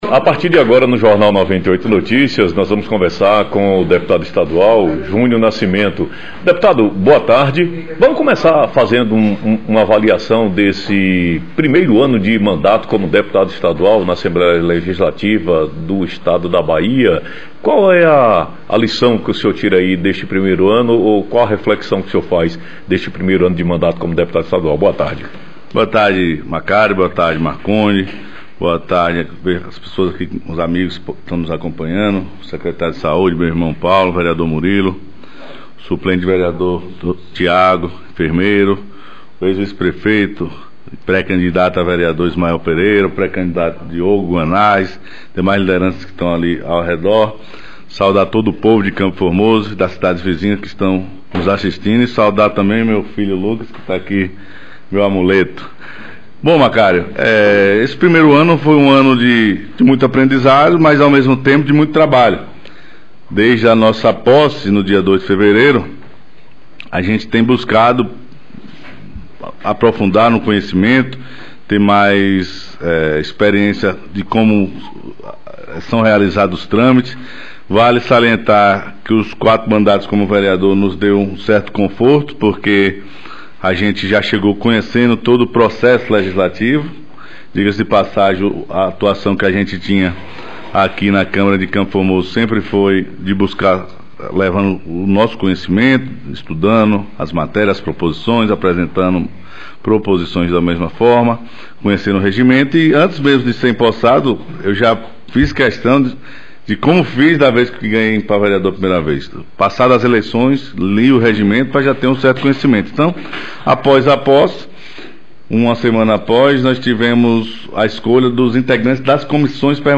Entrevista ao vivo Com o Deputado estadual Júnior Nascimento Estadual Junior Nascimento